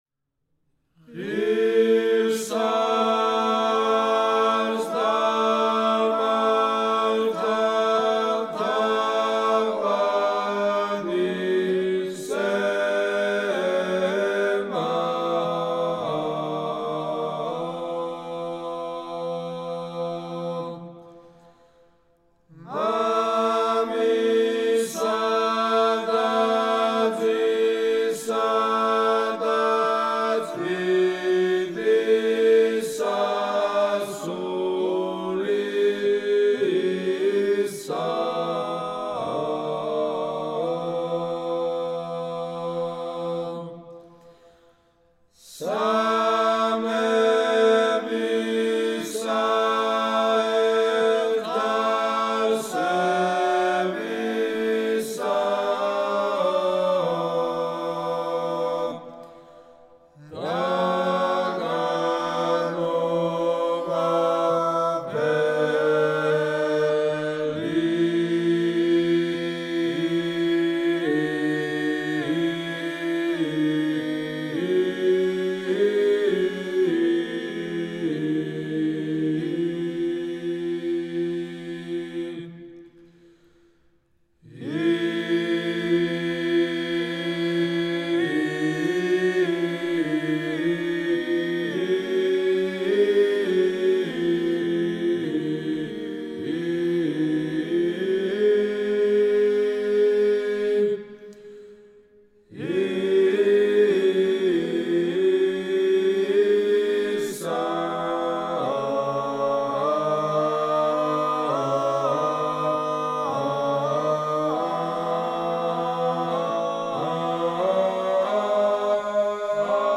საგალობელი: ღირს არს და მართალ გუნდი: აღსავალი სკოლა: ქართლ-კახური (კარბელაანთ კილო)